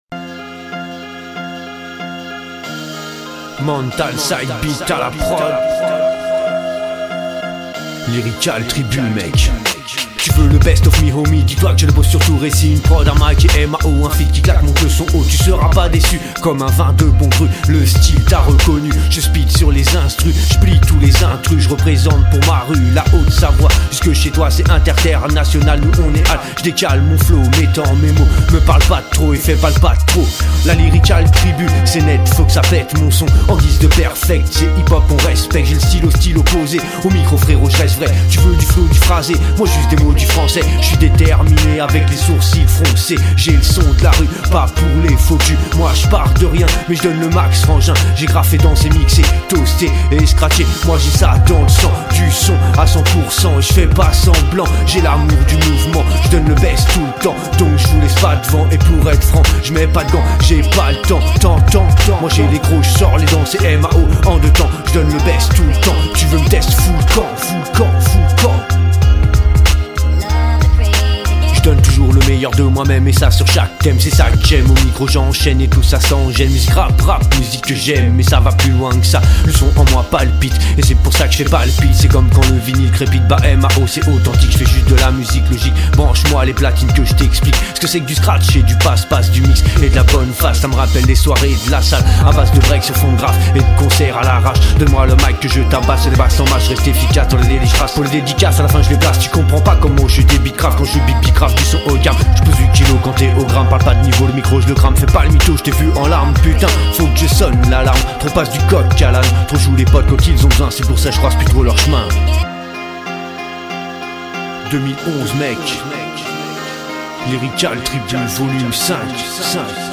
artiste rap . passer découvrir mes musique du rap conscient .